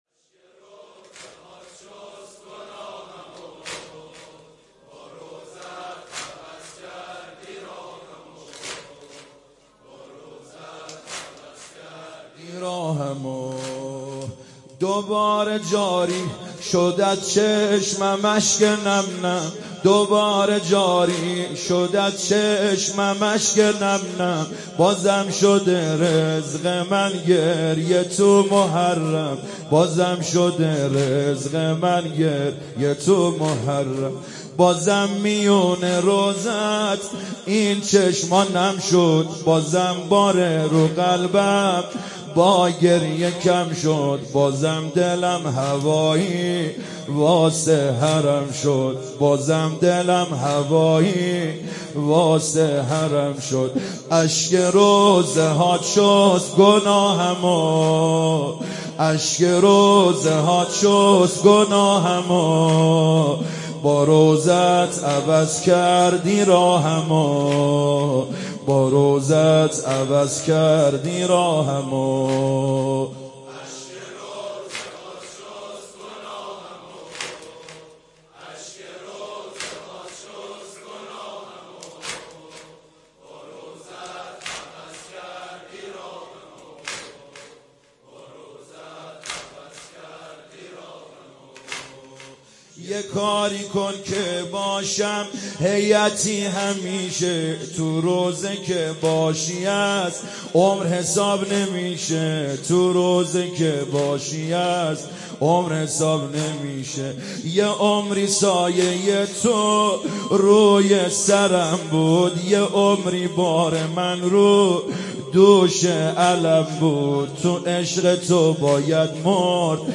شب اول محرم97 هیات کربلا رفسنجان